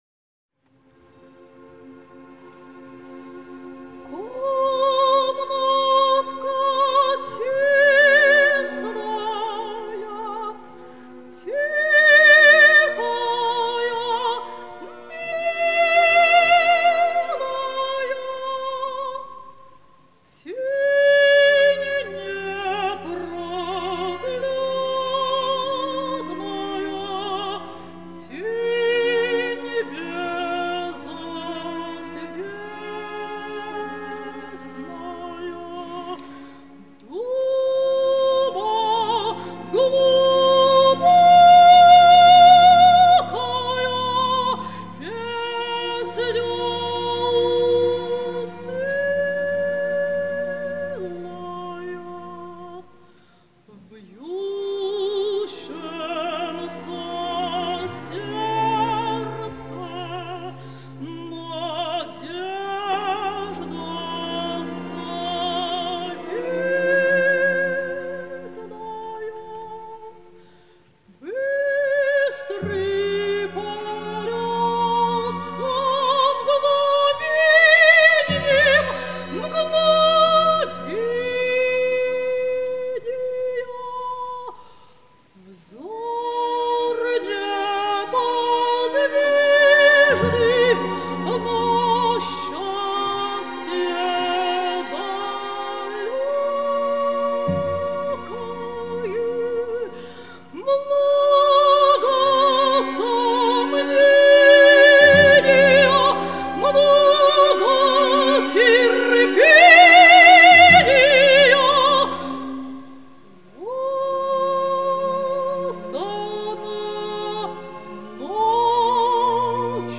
Вокальный цикл